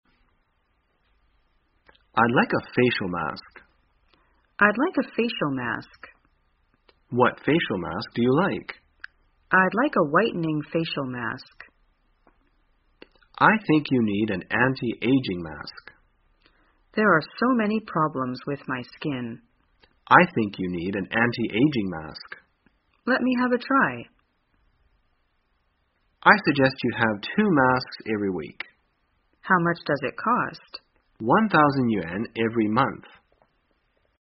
在线英语听力室生活口语天天说 第213期:怎样谈论面膜的听力文件下载,《生活口语天天说》栏目将日常生活中最常用到的口语句型进行收集和重点讲解。真人发音配字幕帮助英语爱好者们练习听力并进行口语跟读。